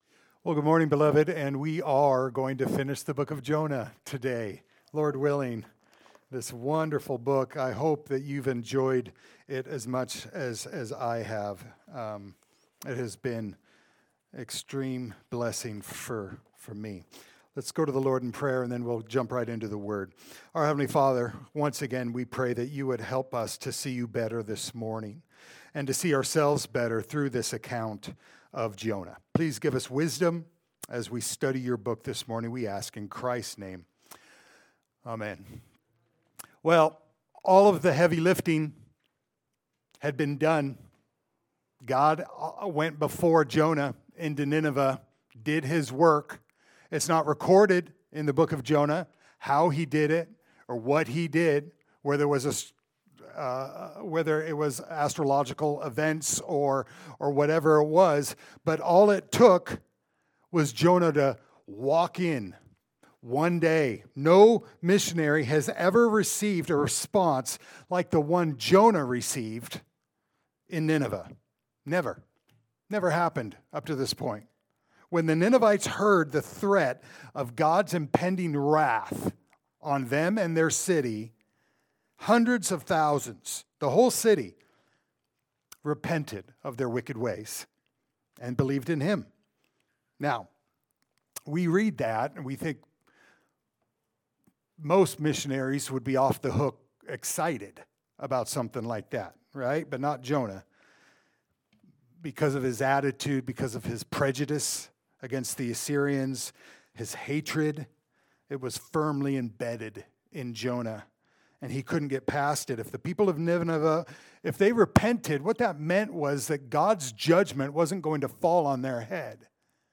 Jonah Passage: Jonah 4:6-11 Service Type: Sunday Service « “Complete My Joy